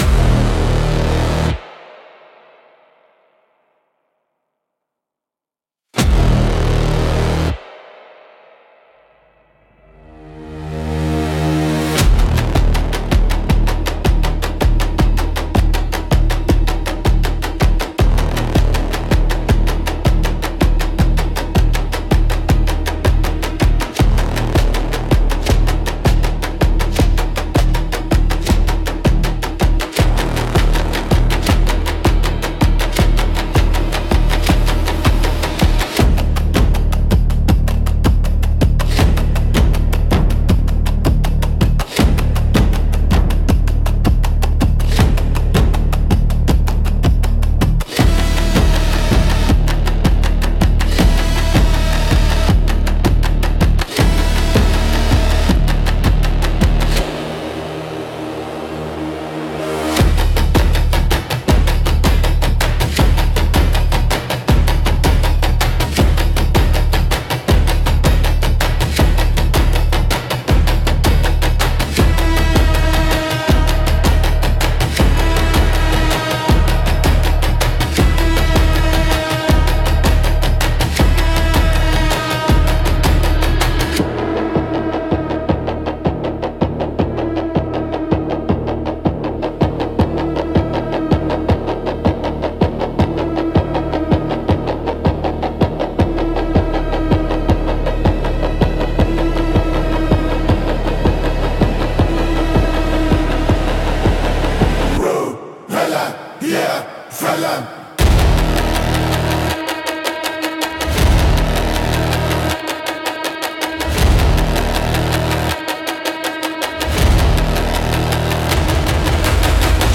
Instrumental - Artificial Ancestry 2.25